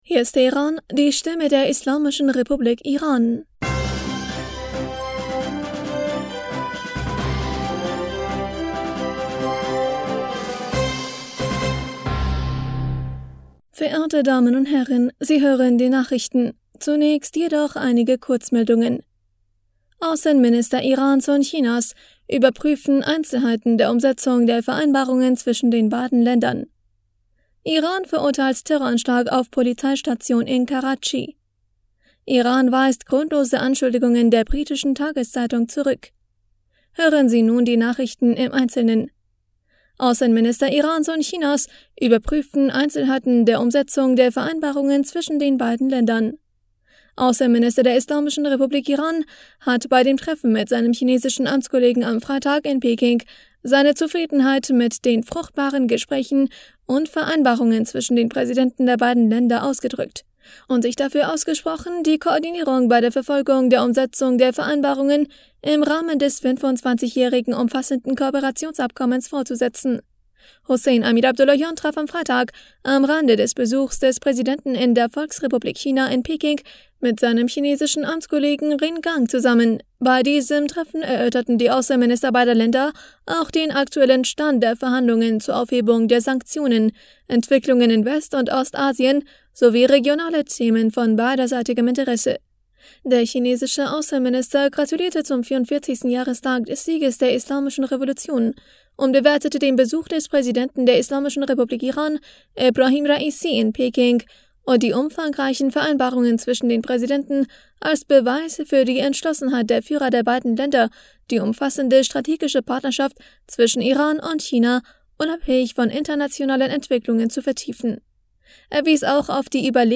Nachrichten vom 18. Februar 2023
Die Nachrichten von Samstag, dem 18. Februar 2023